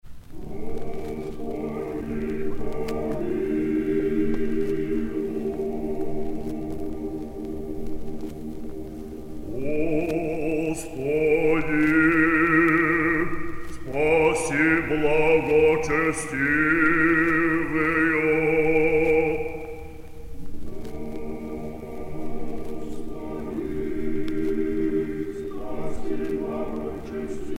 Chants lithurgiques
Pièce musicale éditée